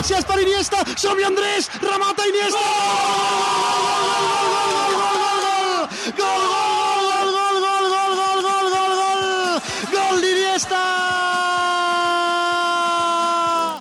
Narració del gol d'Andrés Iniesta a la pròrroga de la final del Campionat del Món de Futbol masculí de Sud-àfrica.
Esportiu